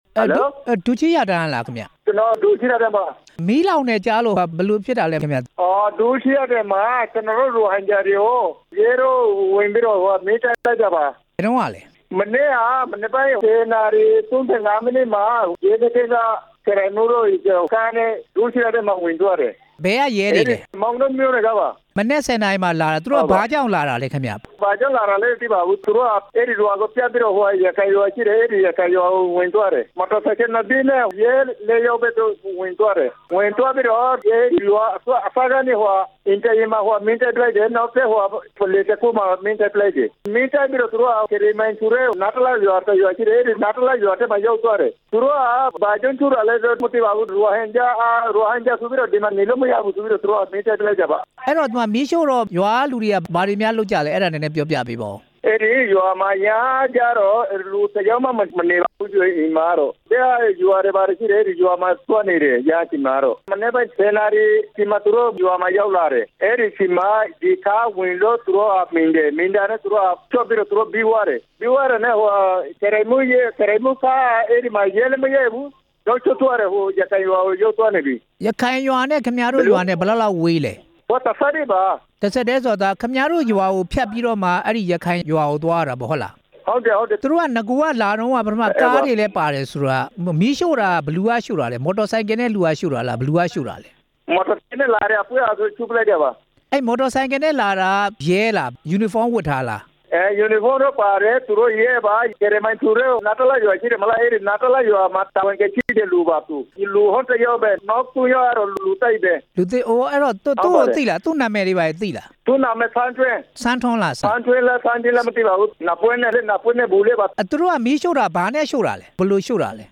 ဒုချီးရားတန်း ရွာသားတစ်ဦးနဲ့ မေးမြန်းချက် နားထောင်ရန်